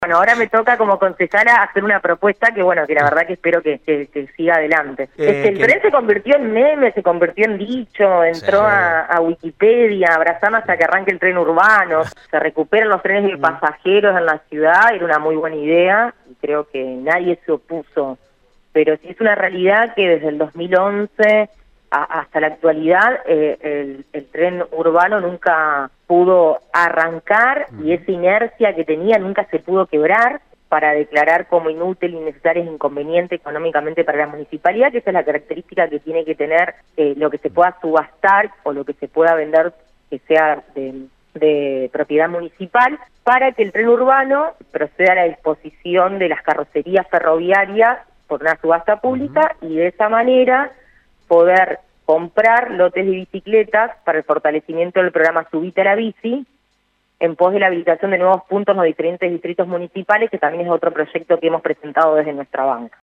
De esta manera lo precisó la concejala Jorgelina Mudallel, del bloque PJ, una de las impulsoras de la iniciativa en Radio EME: «Era una muy buena idea.